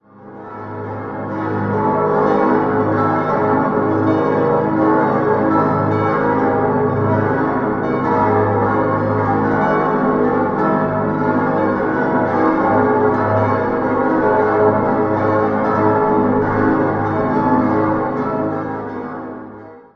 11-stimmiges Geläute: as°-c'-es'-f'-g'-as'-b'-c''-es''-f''-as''
bell
Das glockenreichste und eines der tontiefsten Geläute der Tiroler Landeshauptstadt dürfte das einzige in Tirol sein, in dessen Disposition ein Halbtonschritt bewusst eingeplant wurde.
Wilten_Stiftskirche.mp3